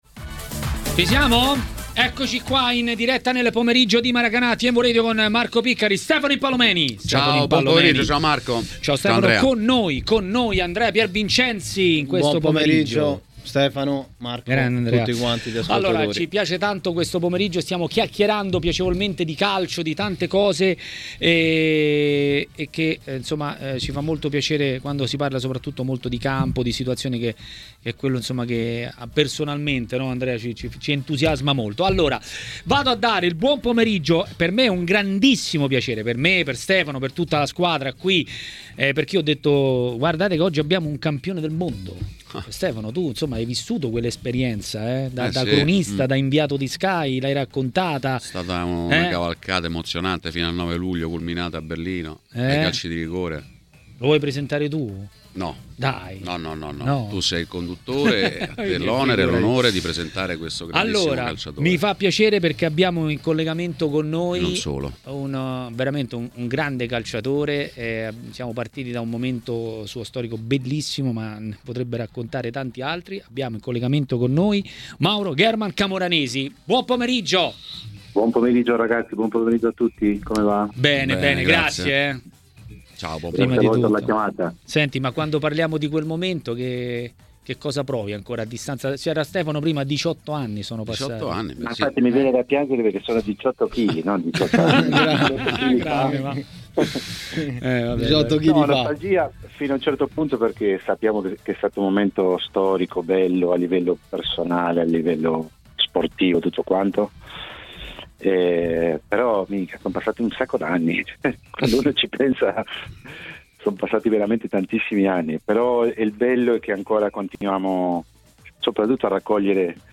A parlare della Serie A ai microfoni di Tmw Radio